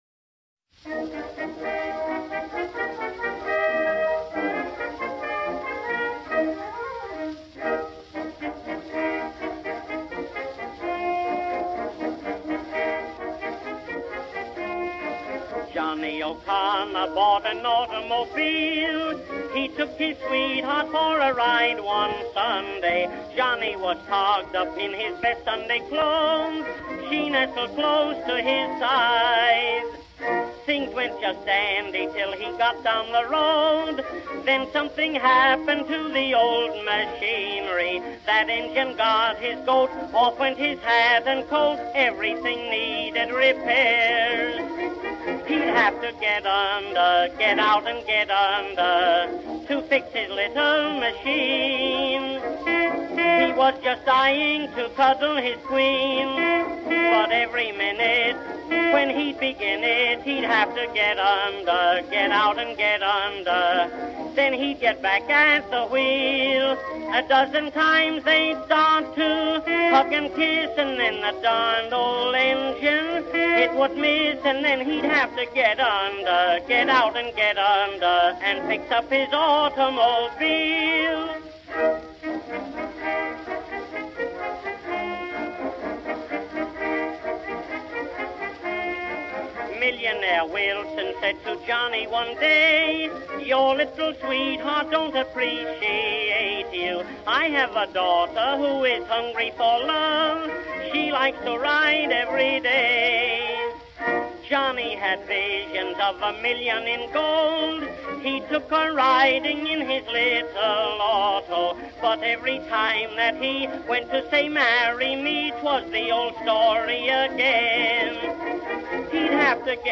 in Eb